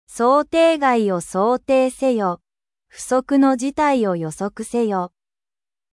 (Google Translateのエンジンを使用した、Sound of Textによるテキスト読み上げ)